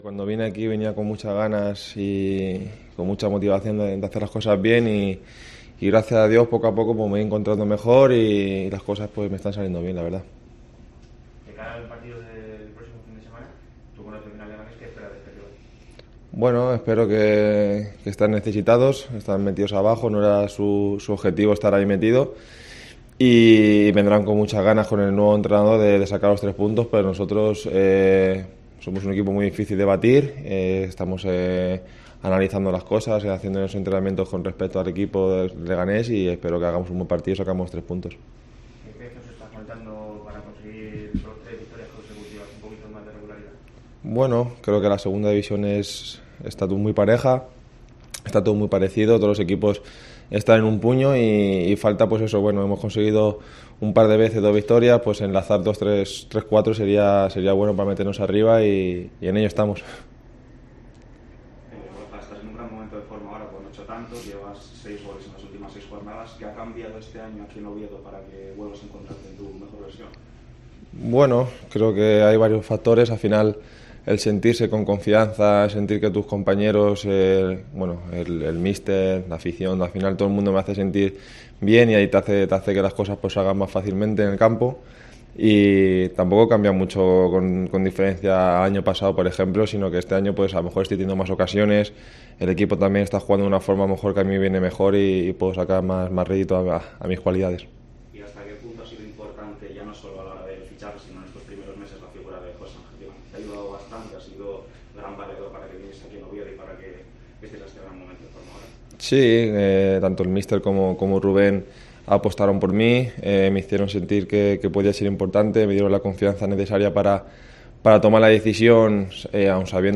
Rueda de prensa Borja Bastón (mejor jugador octubre)